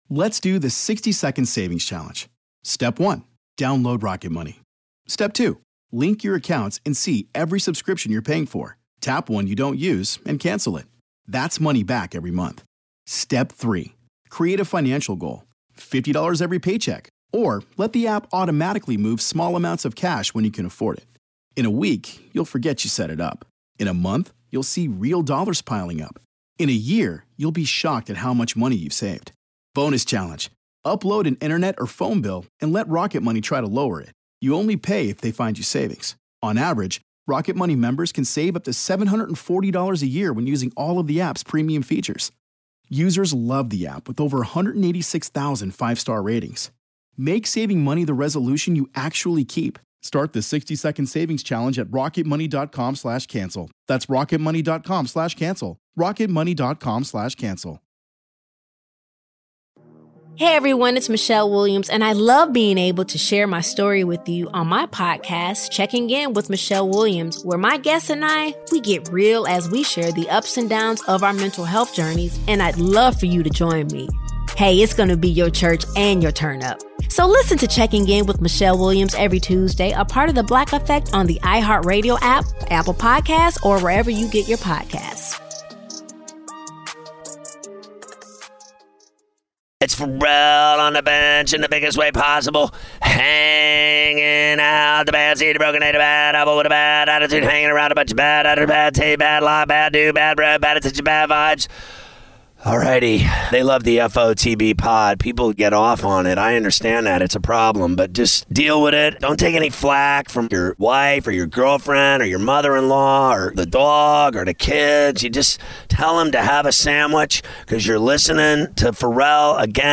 in Part 1 of a 2 part interview about COVID-19 and how he got into medicine